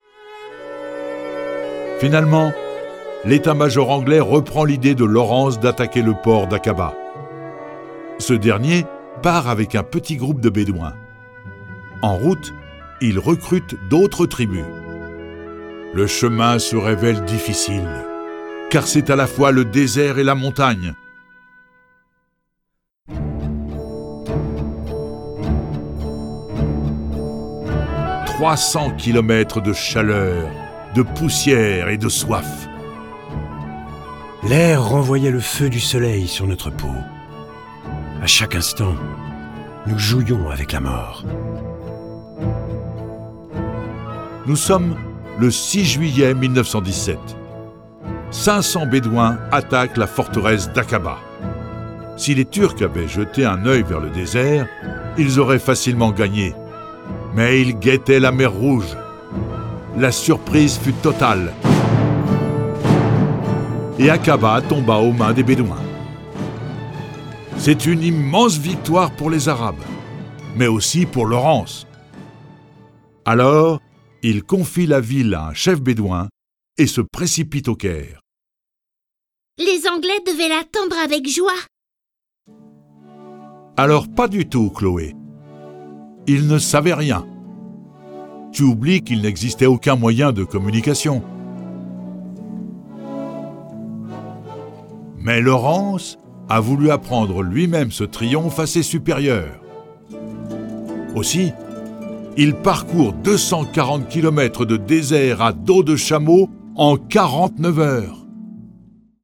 Il est chargé d'aider l'armée arabe qui, au Moyen-Orient, s'est alliée aux Anglais contre les Turcs. Il devient une légende sous le nom de Lawrence d'Arabie. Le récit de sa vie exceptionnelle est animé par 6 voix et accompagné de 35 morceaux de musique classique et traditionnelle